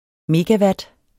Udtale [ megaˈvad ]